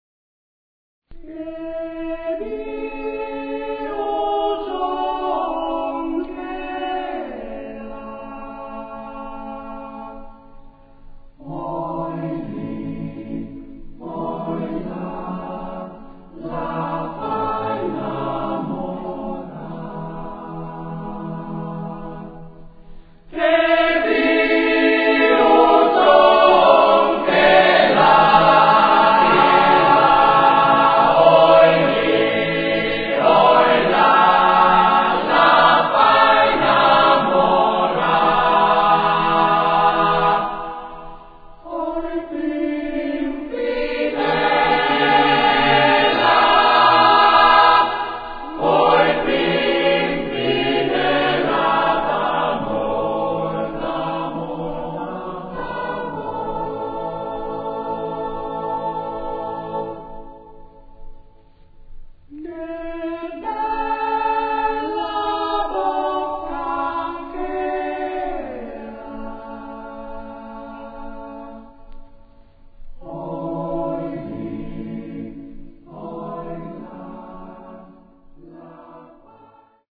Ricerca, elaborazione, esecuzione di canti popolari emiliani